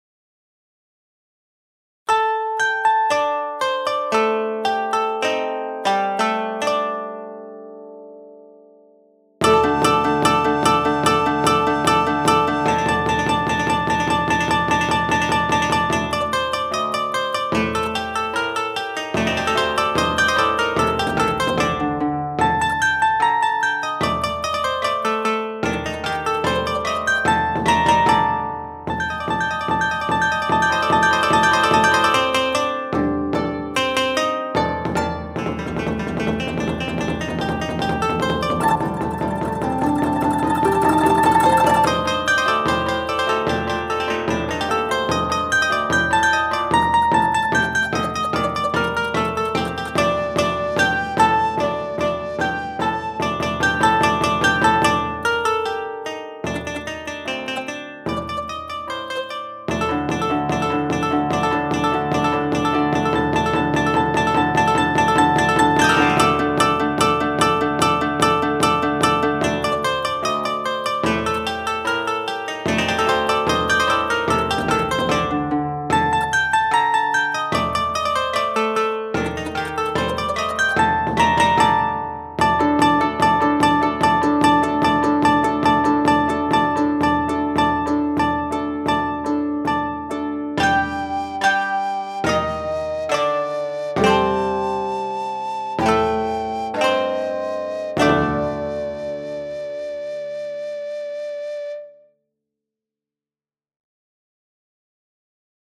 ショート民族激しい